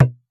Percs
JJPercussion (111).wav